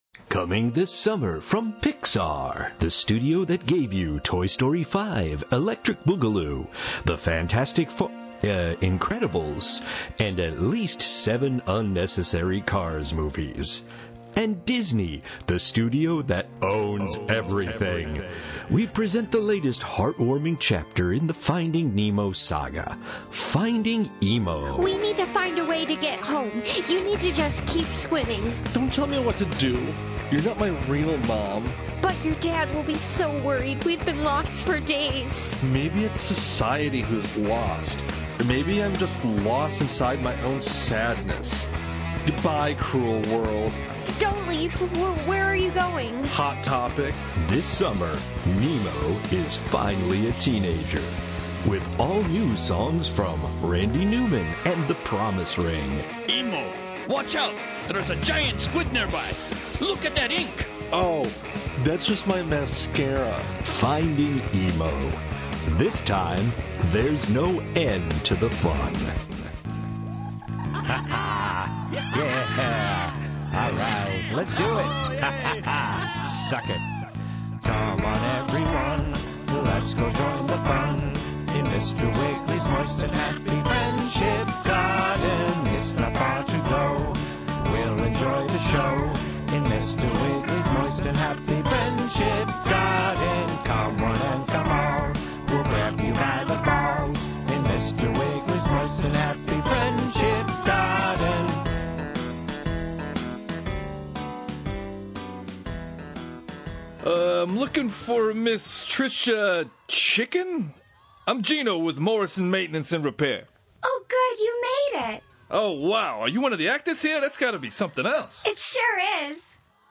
Trolls, robots, and adolescent fish make up part of the menagerie this month. We whisper, shout and sneer our way through a new episode